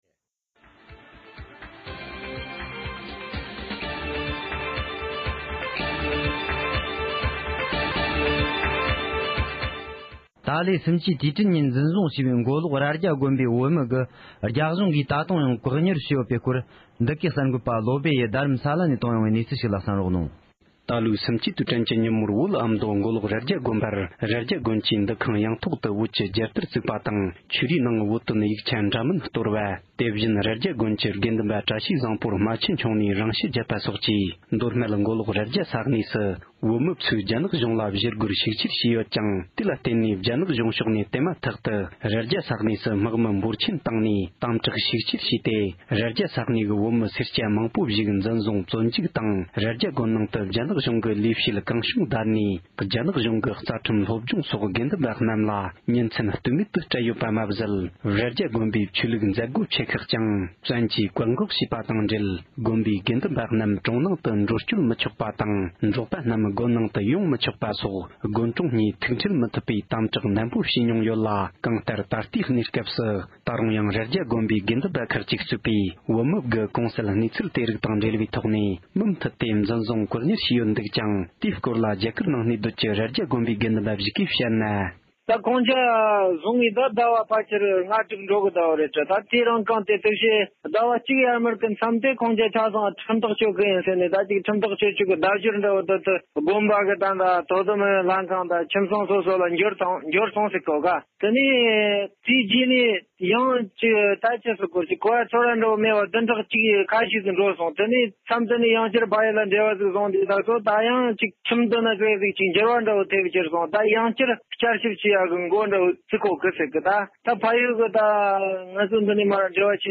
སྒྲ་ལྡན་གསར་འགྱུར། སྒྲ་ཕབ་ལེན།
འབྲེལ་ཡོད་མི་སྣ་ཞིག་ལ་གནས་ཚུལ་བཀའ་འདྲི་ཞུས་པ་ཞིག་ལ་གསན་རོགས་གནོངས